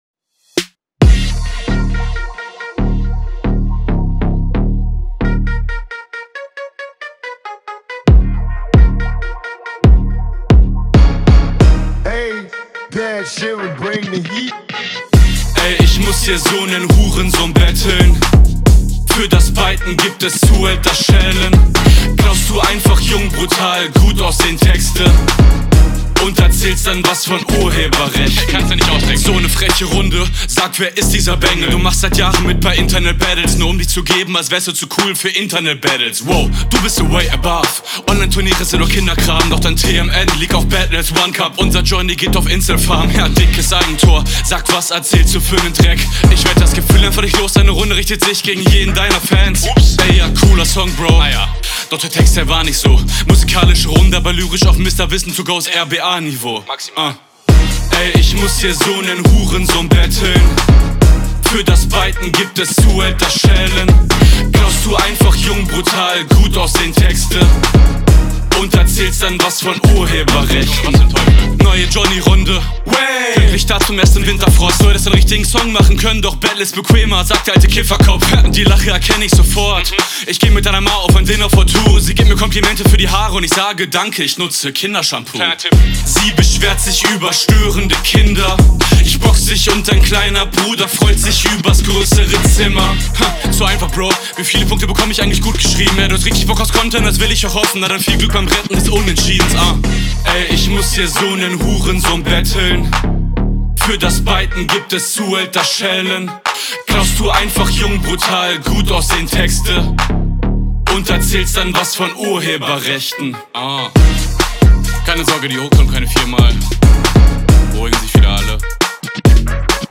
soundtechnisch unterlegen aber kann schon find ich mithalten.
rap ganz gut aber manche flow passagen klingen sehr unroutiniert bzw. nicht sauber, audio auch …